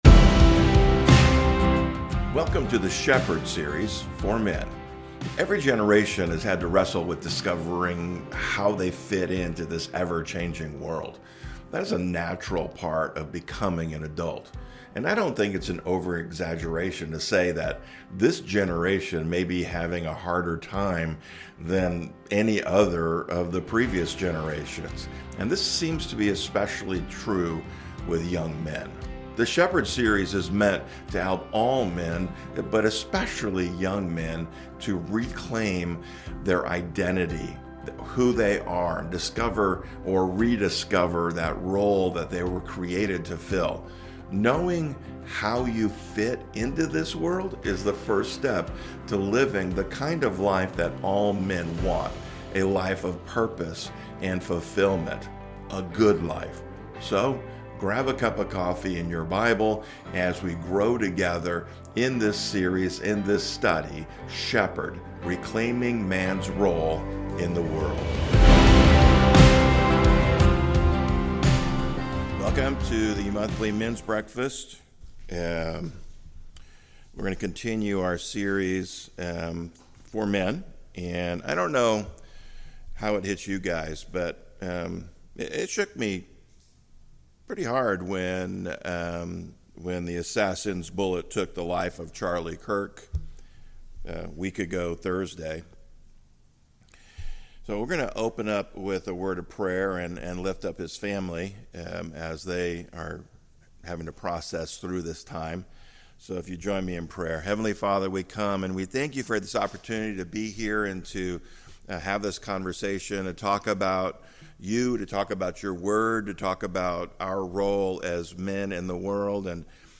SHEPHERD is a series of messages for men.